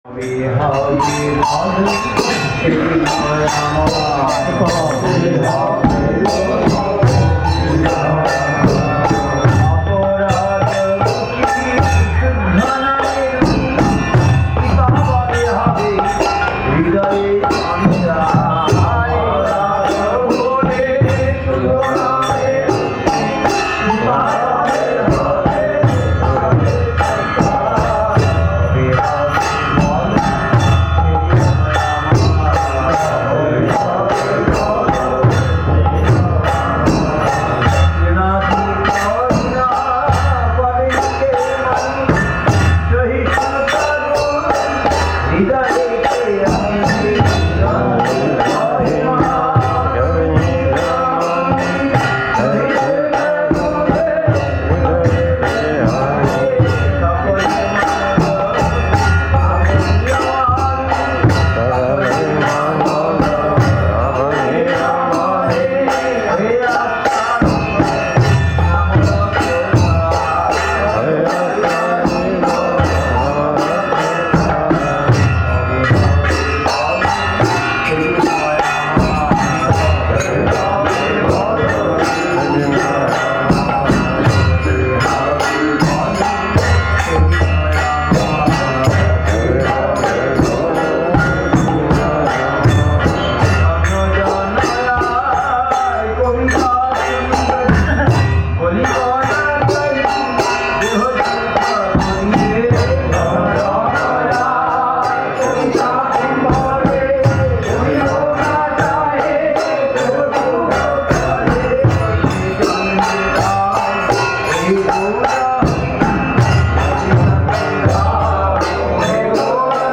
Place: SCSMath Nabadwip
Tags: Kirttan